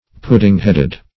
Pudding-headed \Pud"ding-head`ed\, a.
pudding-headed.mp3